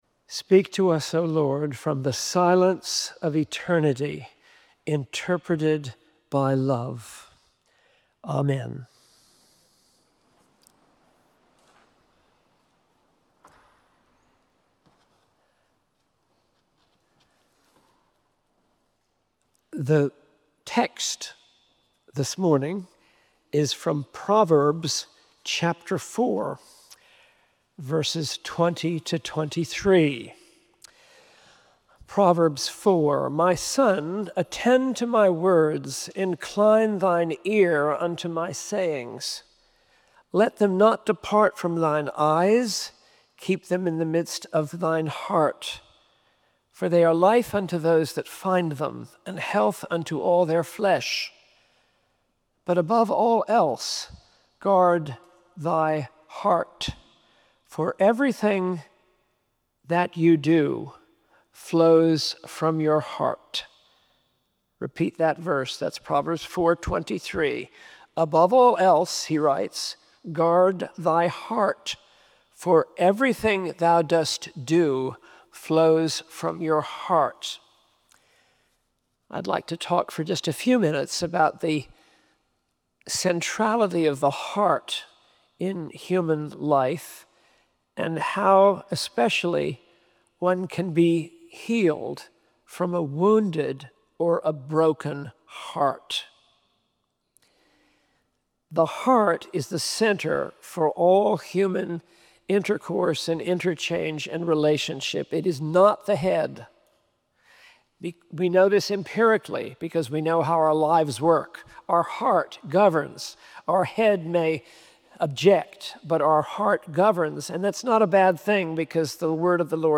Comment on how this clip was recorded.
Preached as part of Advent Birmingham’s Lenten speaker + lunch series. Download Audio Venue: Cathedral Church of the Advent Birmingham Scripture: Proverbs 4:20-23